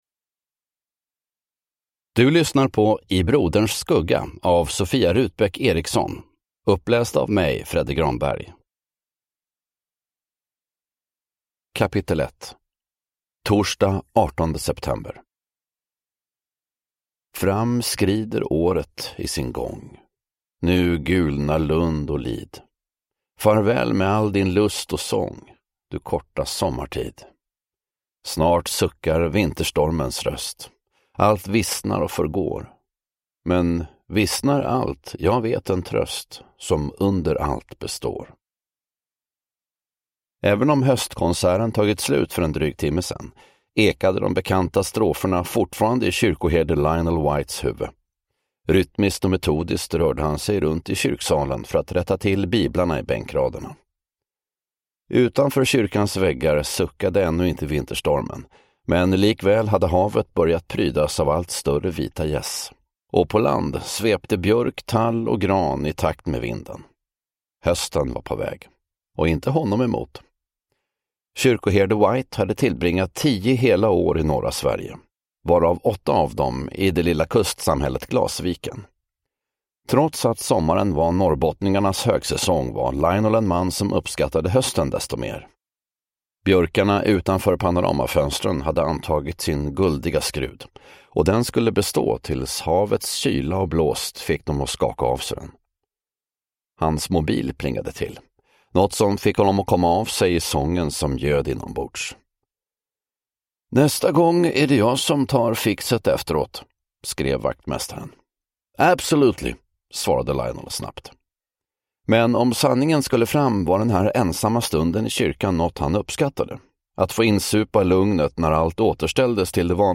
I broderns skugga (ljudbok) av Sofia Rutbäck Eriksson